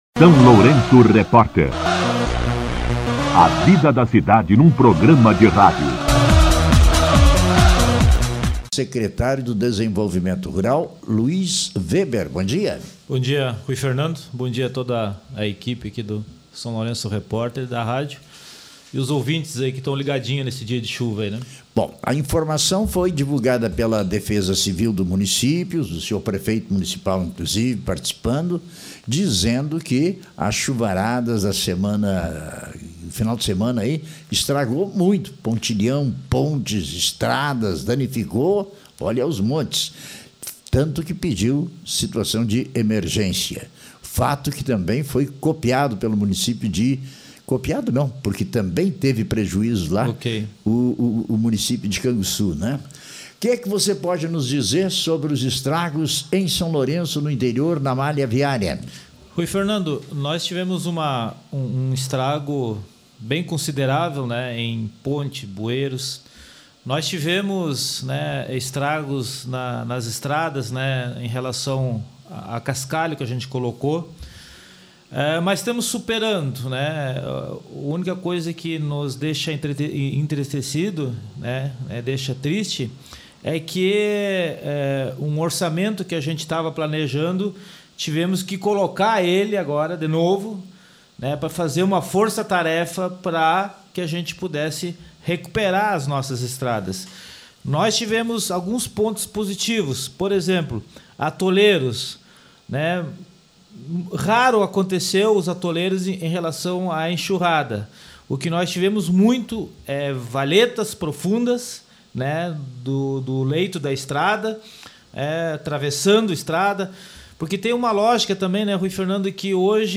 O secretário de Desenvolvimento Rural, Luis Weber, concedeu entrevista ao SLR RÁDIO na manhã desta quinta-feira (4) para atualizar a situação das estradas do interior após as fortes chuvas que assolam São Lourenço do Sul.